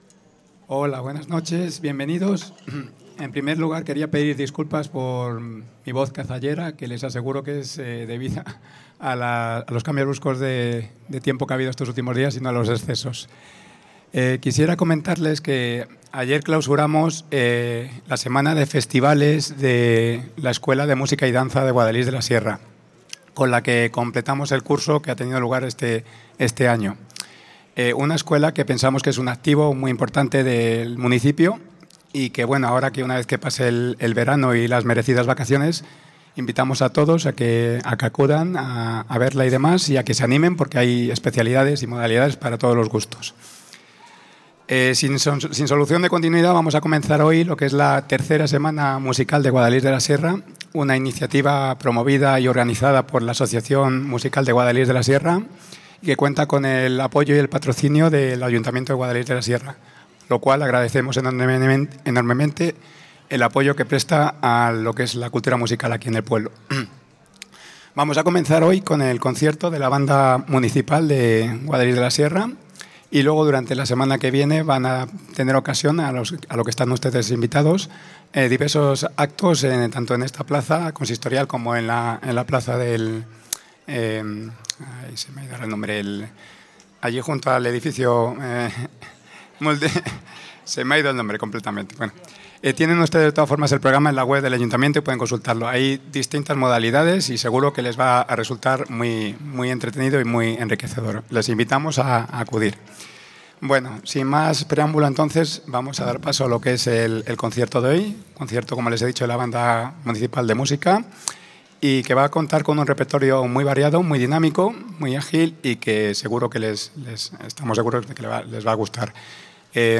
Concierto inicio Semana Musical
concierto banda municipal semana musical.mp3